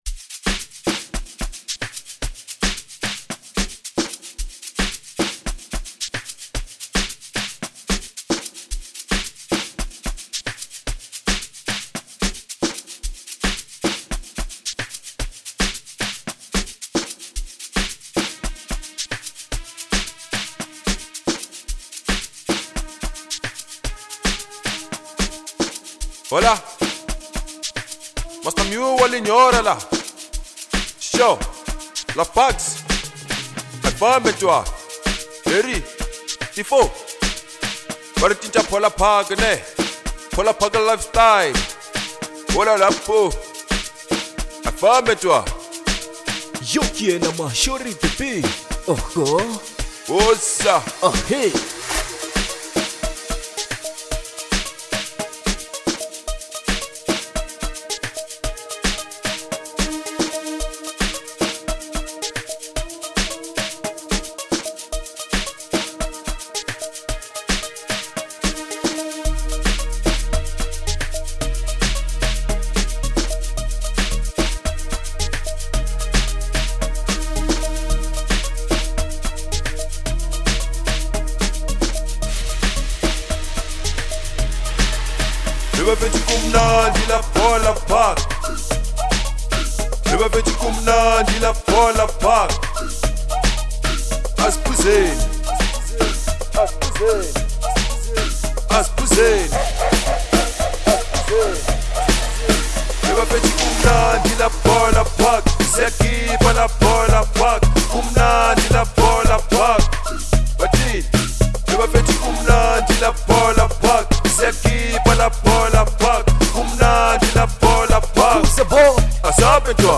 05:17 Genre : Amapiano Size